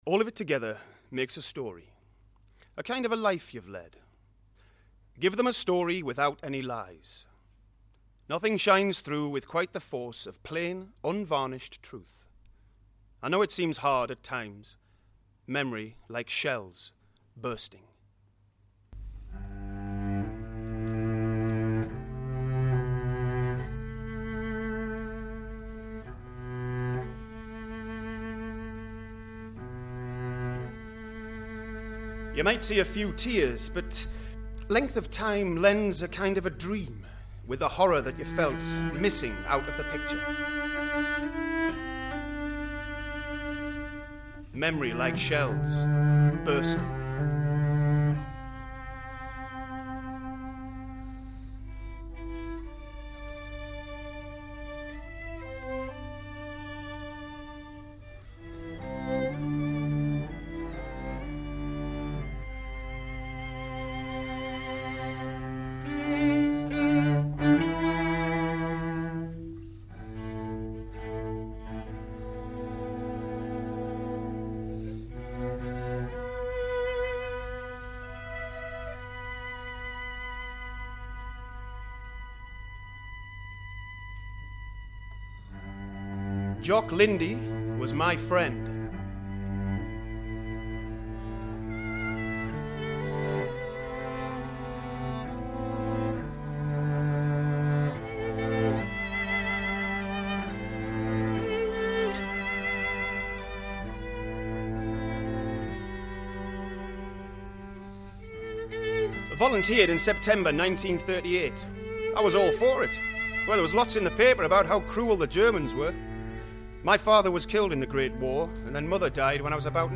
A performance